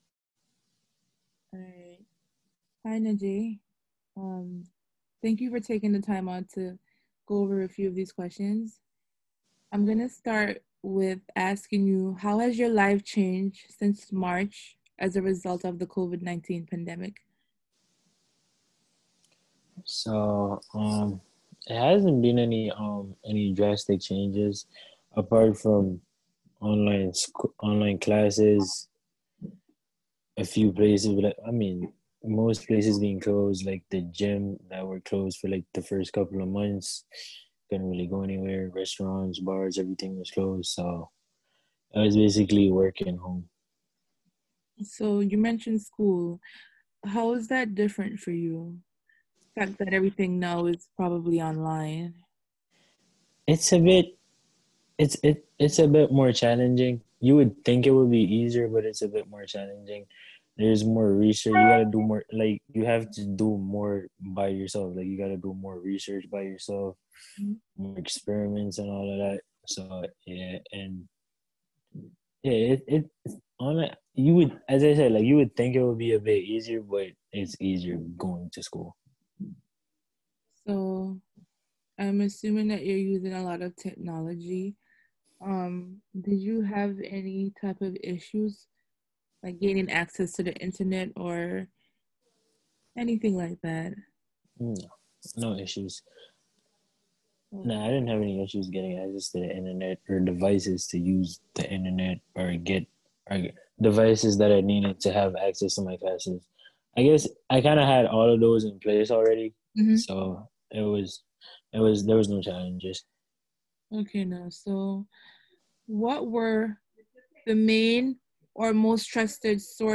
Participant 499 Community Conversations Interview